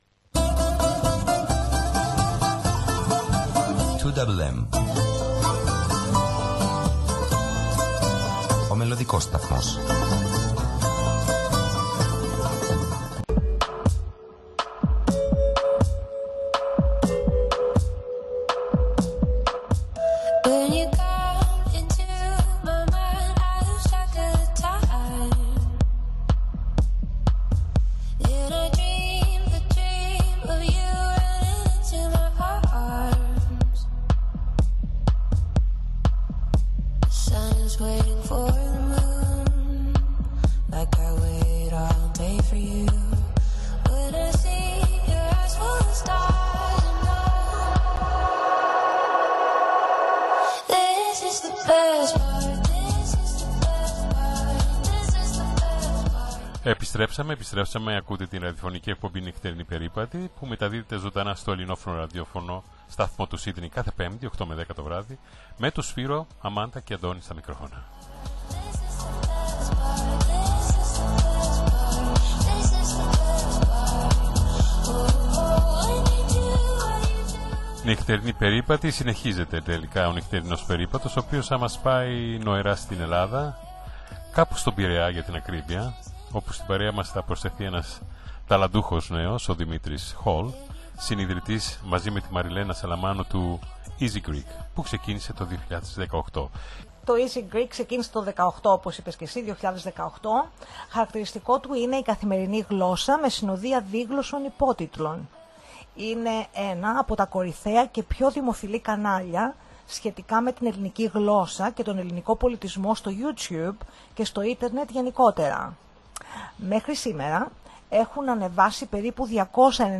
μίλησε ζωντανά στην εκπομπή